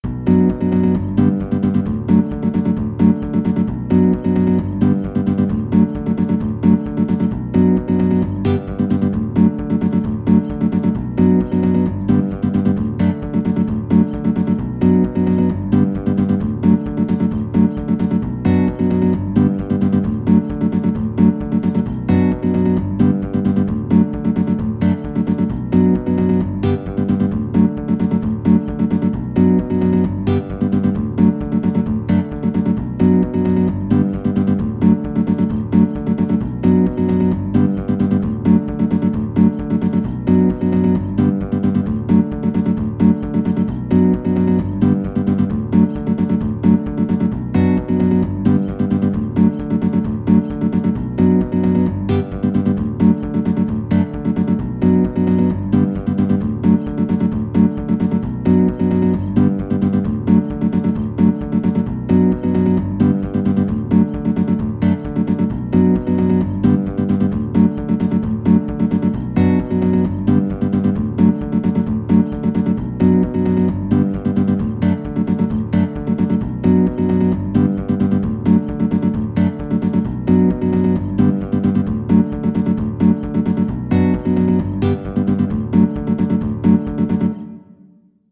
Guitar version
4/4 (View more 4/4 Music)
Guitar  (View more Easy Guitar Music)
Pop (View more Pop Guitar Music)